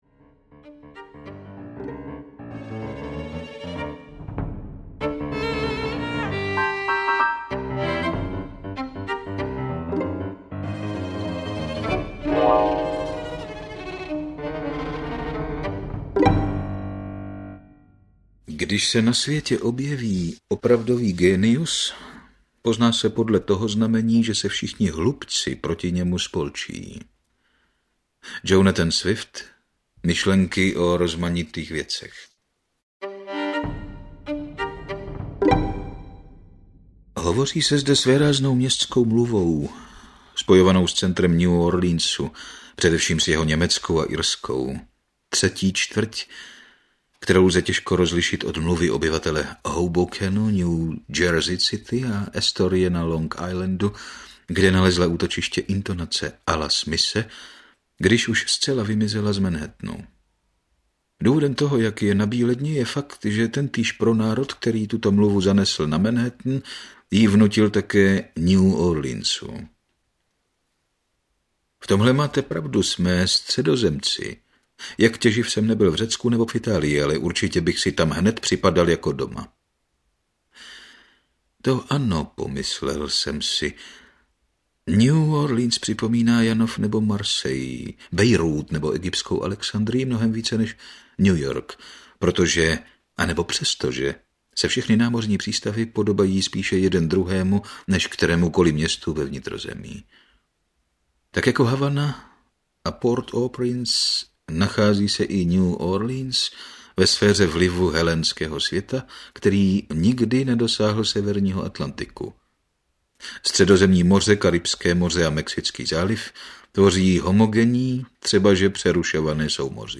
Spolčení hlupců audiokniha
Ukázka z knihy